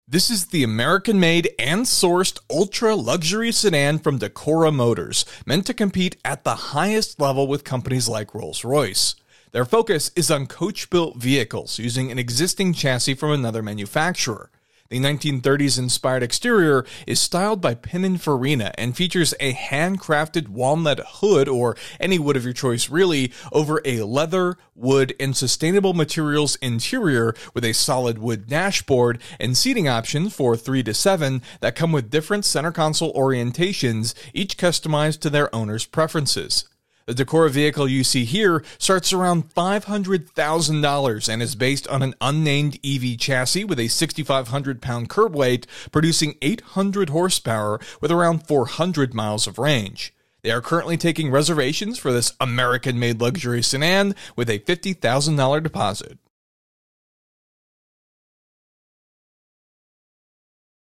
American Made Ultra Luxury Sedan